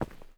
mining sounds
ROCK.2.wav